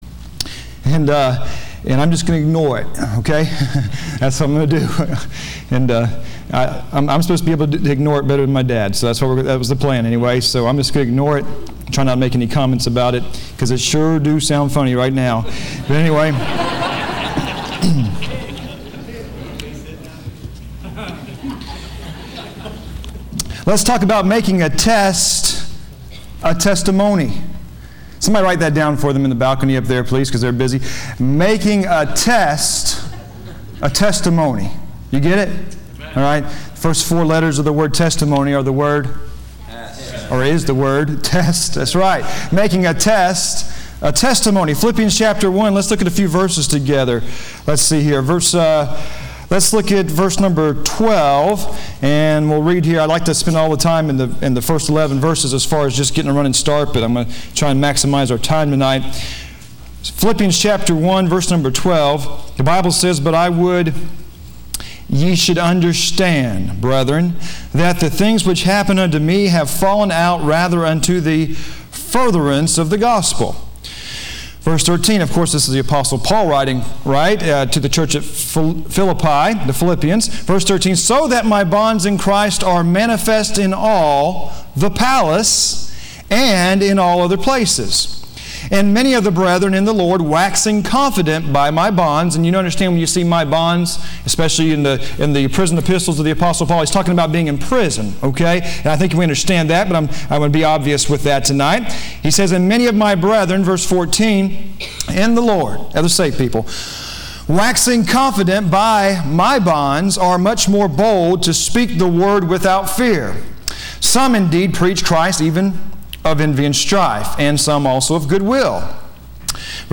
Listen to Message
Service Type: Wednesday